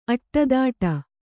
♪ aṭṭadāṭa